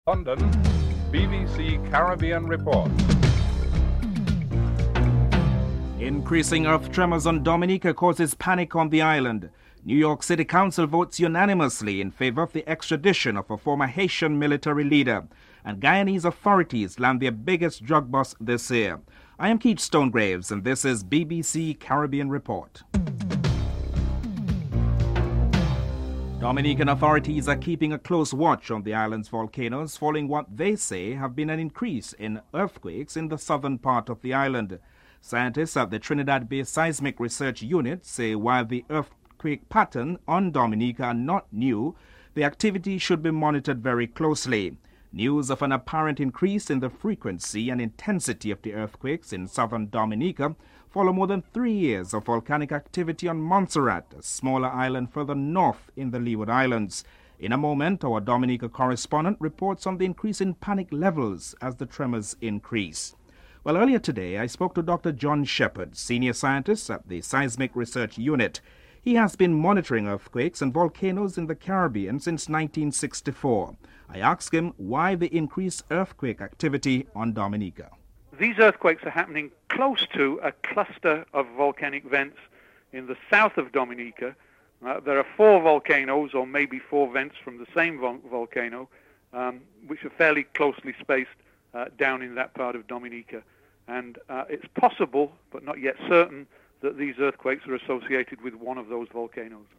4. CARICOM leaders step up the lobbying in the United States to head off the growing trade impasse with Europe over banana imports from the Caribbean. CARICOM Chairman Prime Minister Kenny Anthony is interviewed (10:33-11:56)